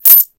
Spend_Currency.ogg